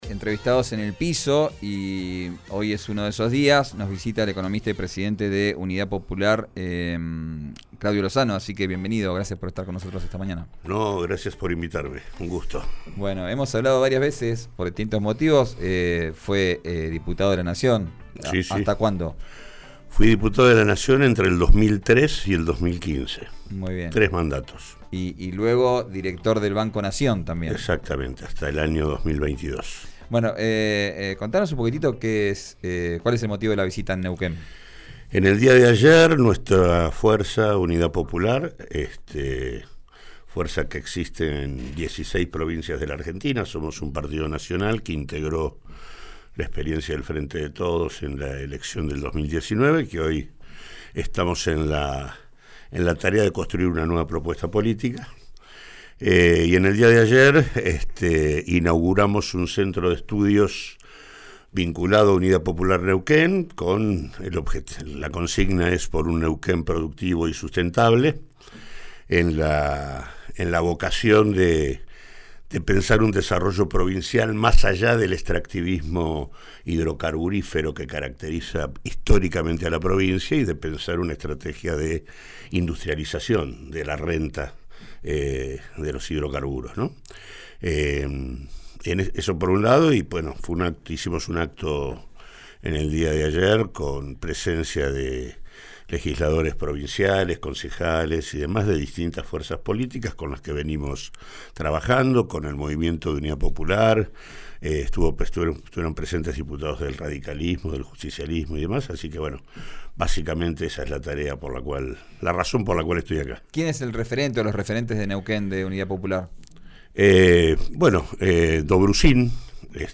En diálogo presencial en los estudios de RÍO NEGRO RADIO, el dirigente destacó la construcción que su espacio lleva adelante en todo el país, luego de la experiencia fallida como parte del Frente de Todos que llevó a la presidencia a Alberto Fernández.